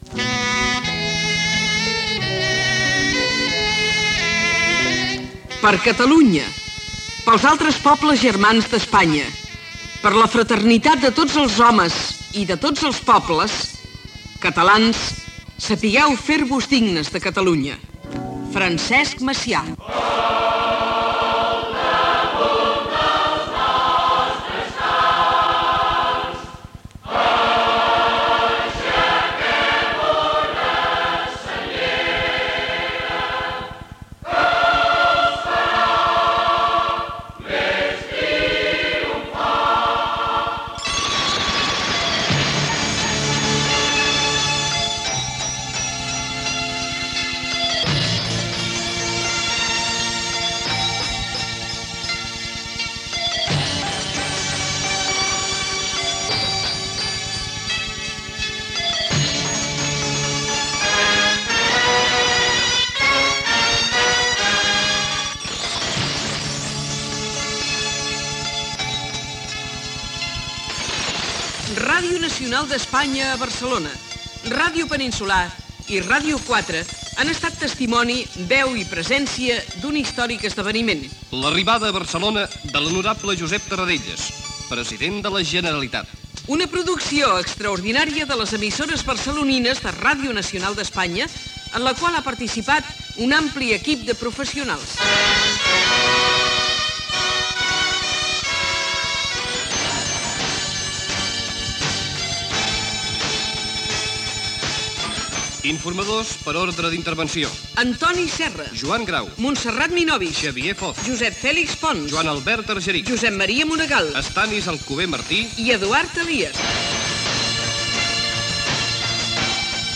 Transmissió del retorn del president de la Generalitat Josep Tarradellas. Dita de Macià, Cant de la senyera i careta final amb els noms de l'equip.
Informatiu